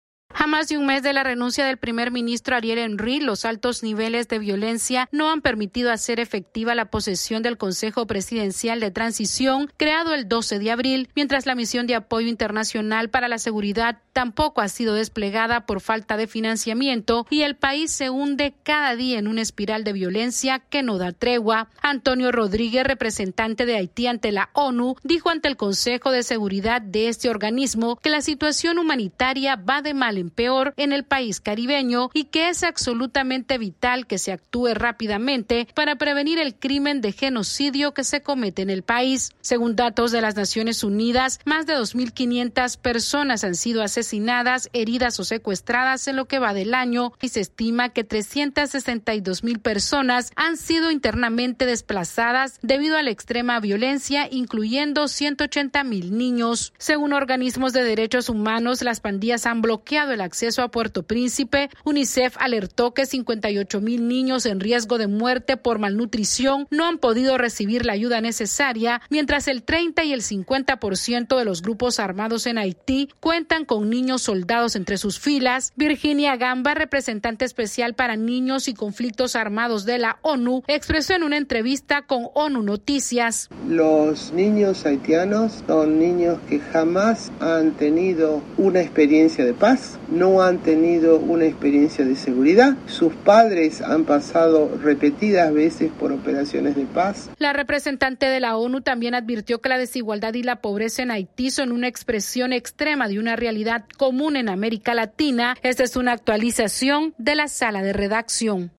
AudioNoticias
Los altos niveles de violencia en Haití no han permitido hacer efectiva la posesión del Consejo Presidencial de Transición y la Misión de Apoyo para la Seguridad tampoco ha sido desplegada mientras miles de niños sufren el conflicto armado. Esta es una actualización de nuestra Sala de Redacción.